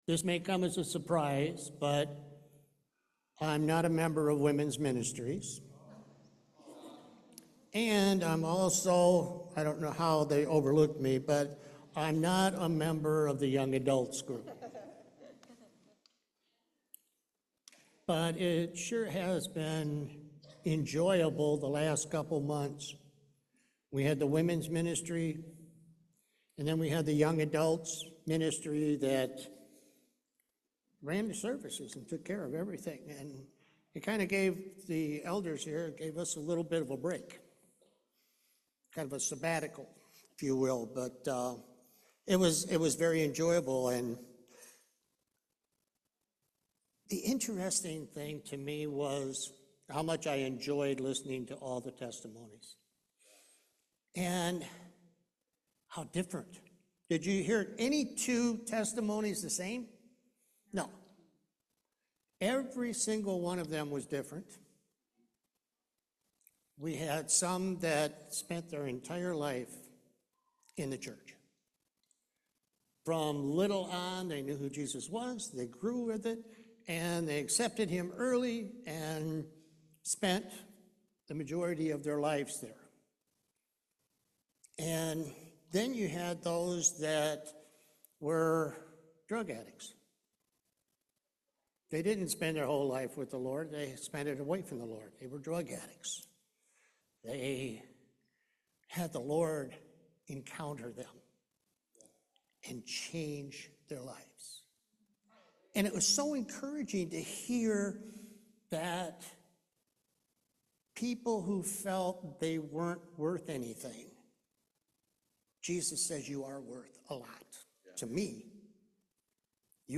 Galatians 5:13 Service Type: Main Service It doesn’t matter when you get saved